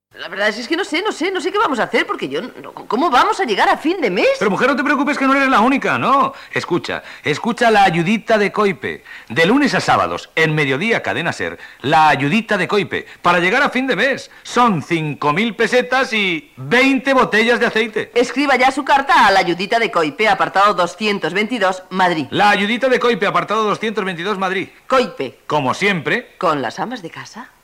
Promo de la secció "La ayudita de Koipe"
Entreteniment